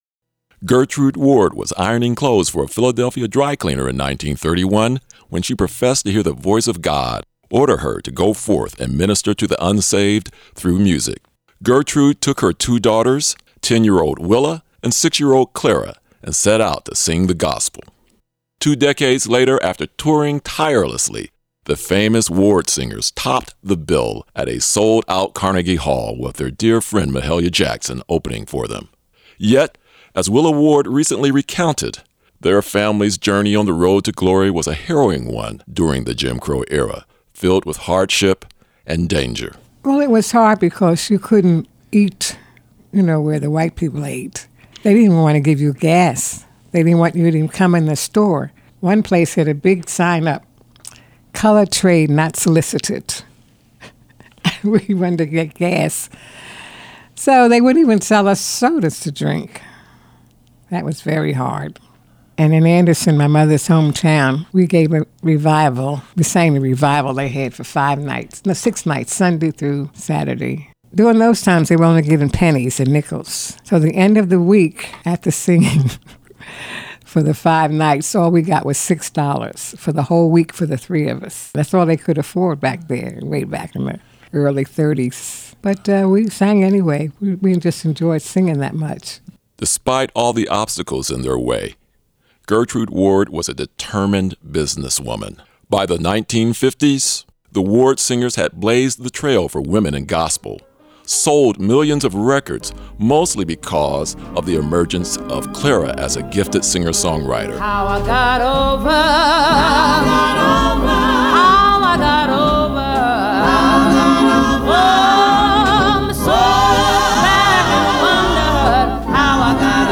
Interviews and Documentaries